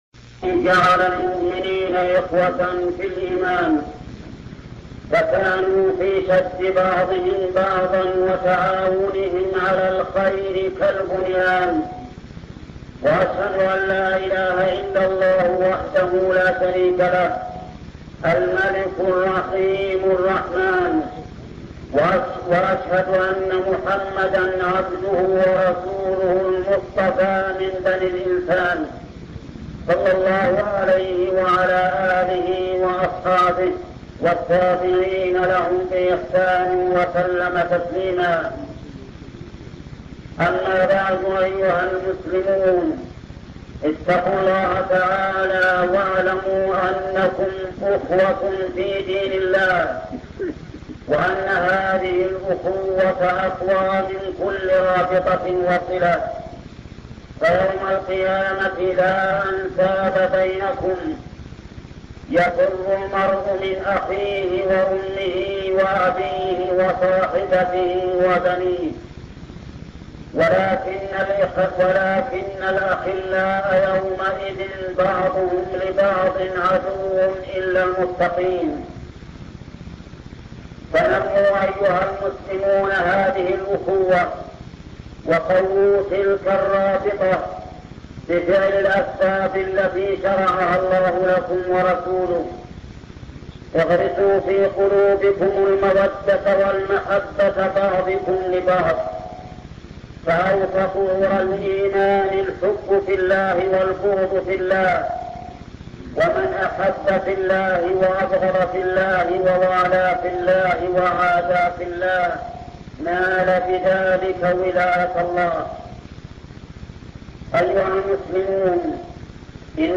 خطب الجمعة - الشيخ محمد بن صالح العثيمين